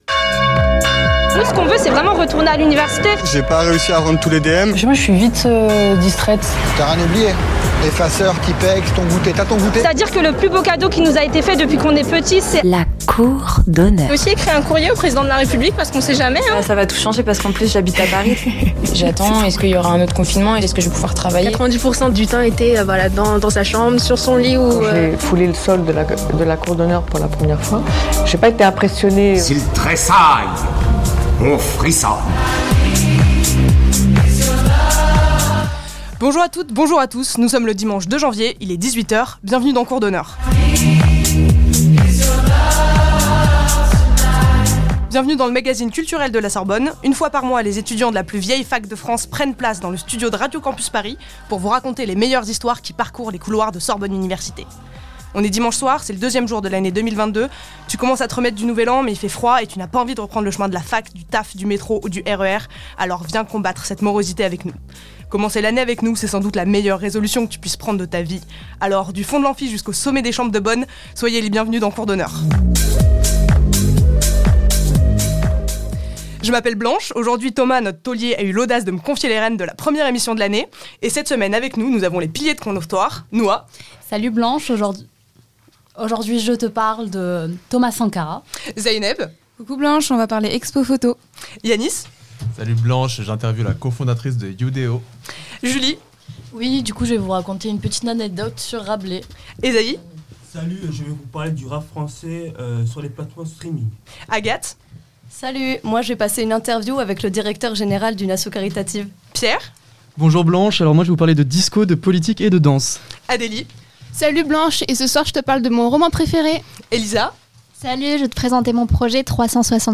Bienvenu dans le magazine culturel de la Sorbonne
Une fois par mois, les étudiants de la plus vieille fac de France prennent place dans le studio de Radio Campus Paris pour vous raconter les meilleures histoires qui parcourent les couloirs de Radio Sorbonne Université.